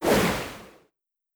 tele_water.wav